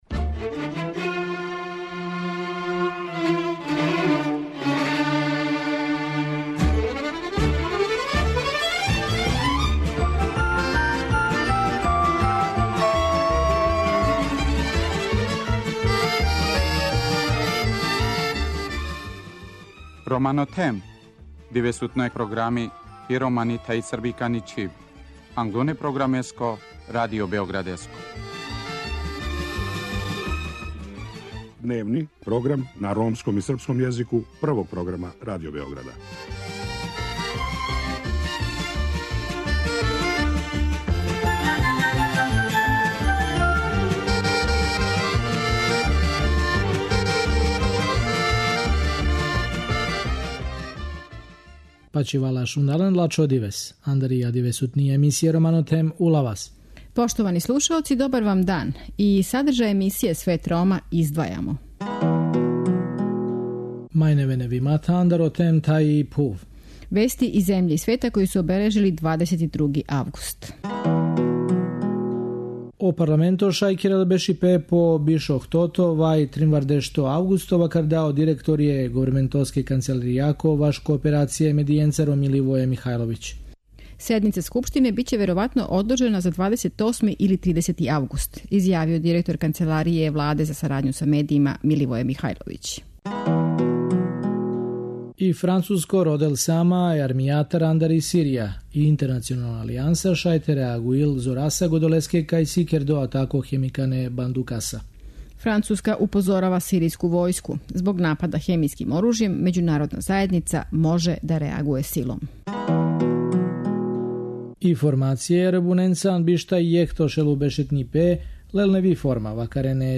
Сратегија за унапређење положаја Рома у Републици Србији је дела неке резултате, али она остаје велики изазов јер њени основни циљеви још нису испуњени. О томе разговарамо са замеником Заштитника грађана, Гораном Башићем.